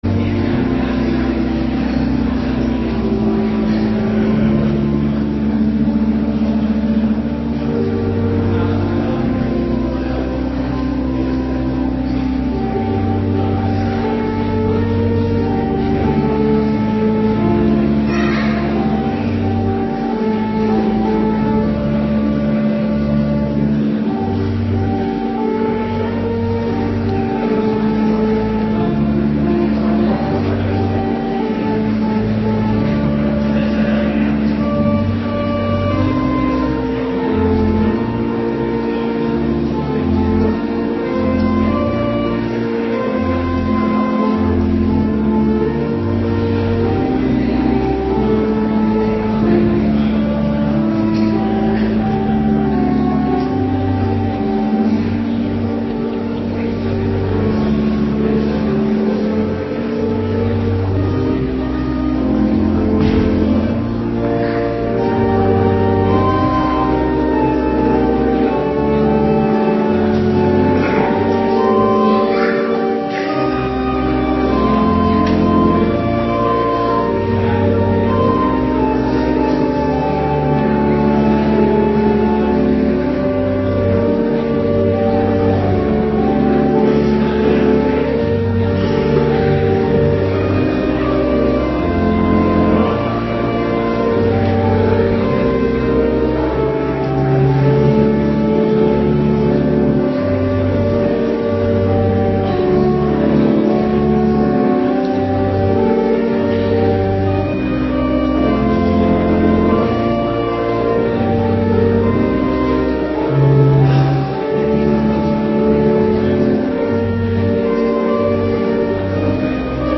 Morgendienst 14 december 2025